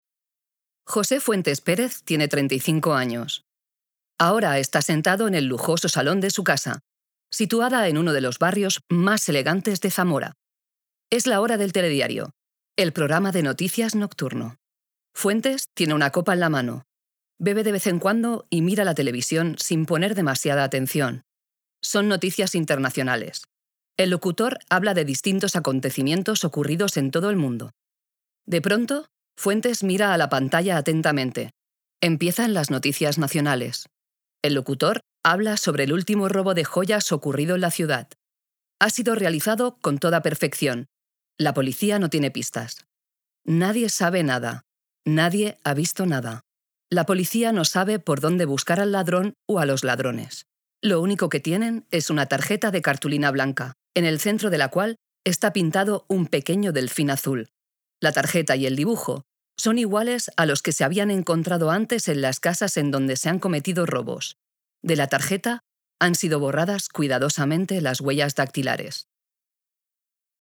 spanish voice over actress with my own studio documentary animation corporate animation dubbing advertising song e-learning e-learning audio book audio guide audio guide audio description speed seriousness commitment
kastilisch
Sprechprobe: Industrie (Muttersprache):